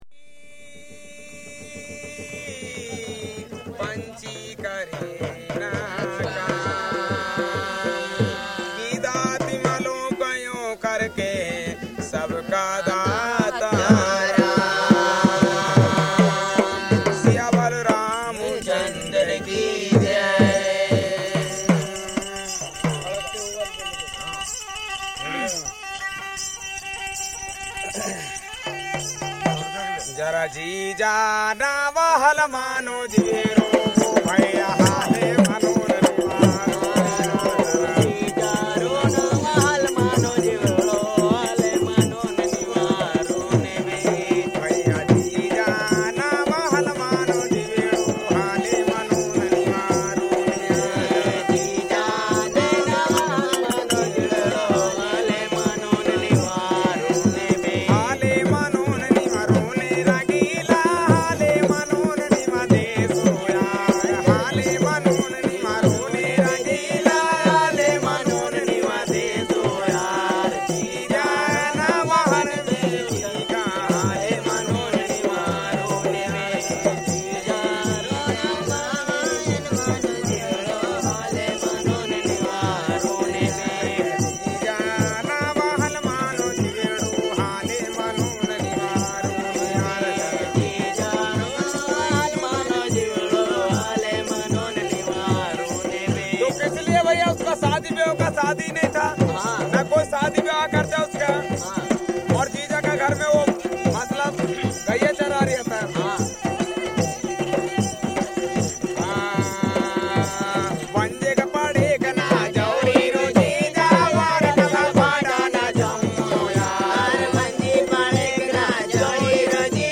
Men singing, with percussion and violin
From the sound collections of the Pitt Rivers Museum, University of Oxford, being from a collection of cassette tape recordings of songs and instruments
across several different states in India during 1987.